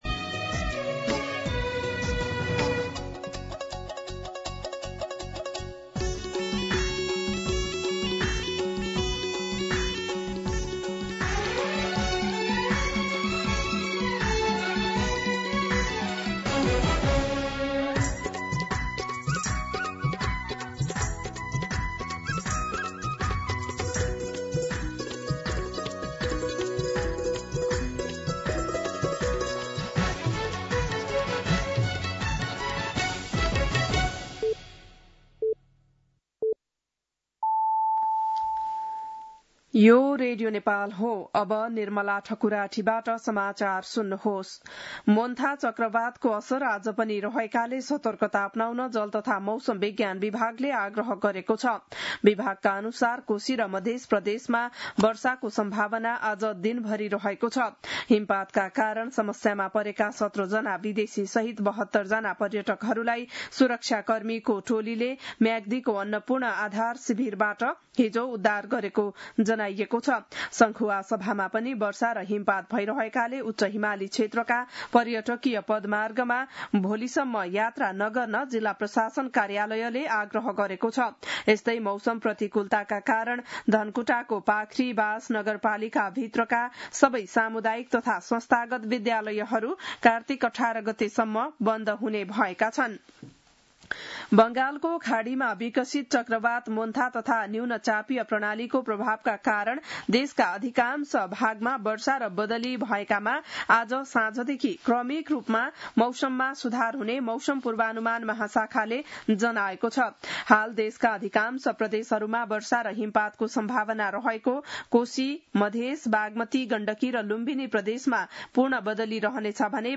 बिहान ११ बजेको नेपाली समाचार : १४ कार्तिक , २०८२
11-am-Nepali-News-11.mp3